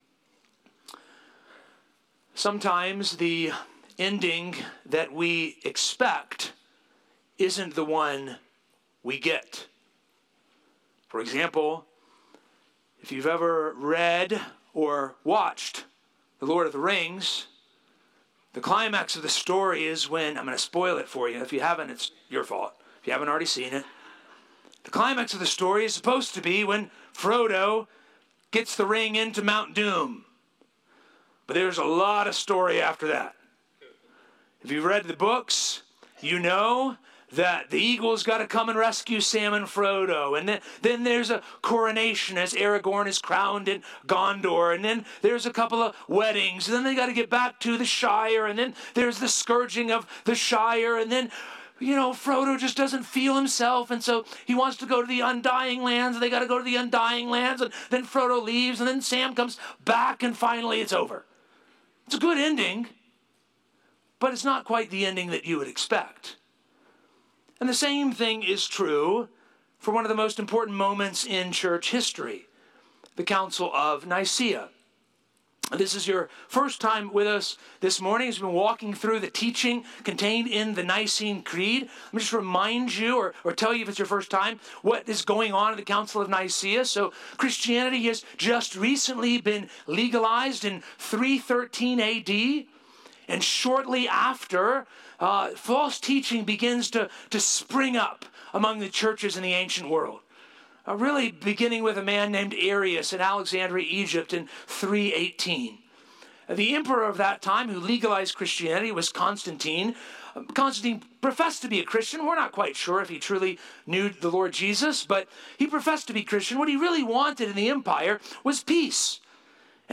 Sermons | Poquoson Baptist Church